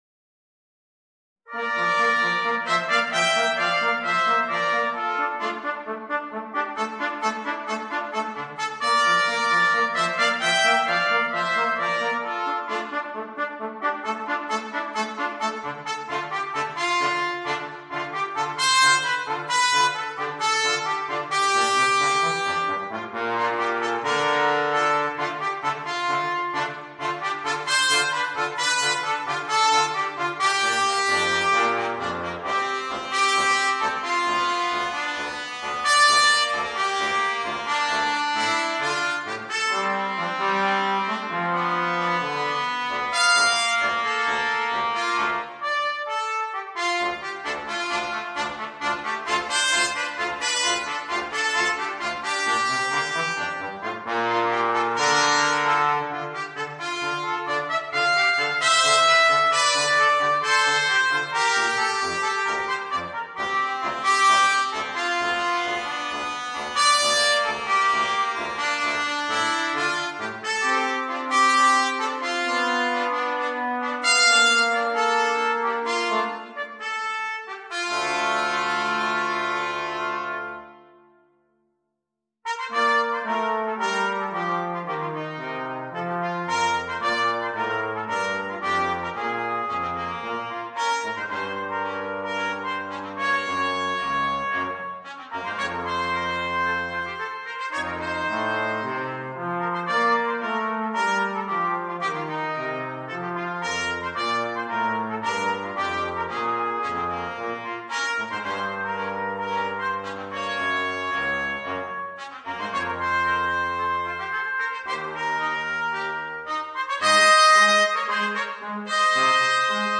2 Trumpets (Cornet) & Euphonium